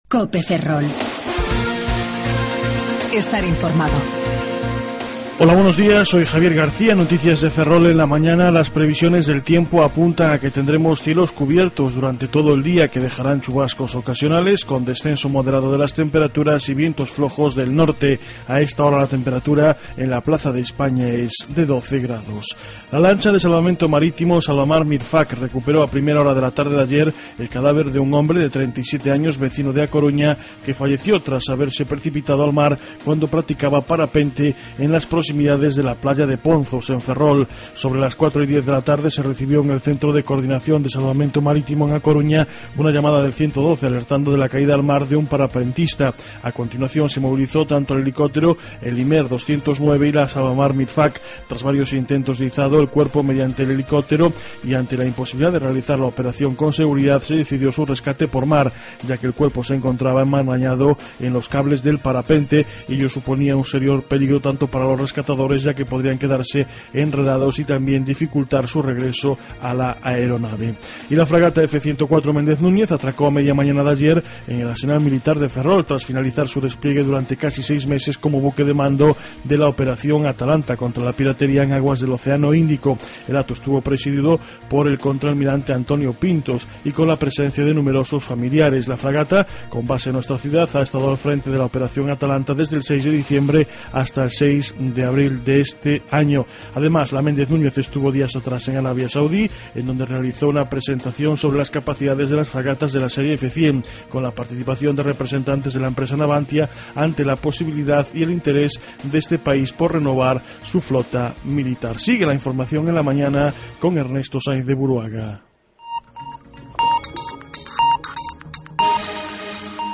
07:58 Informativo La Mañana